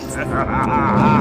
"(Evil laugh)"
evil_laugh.mp3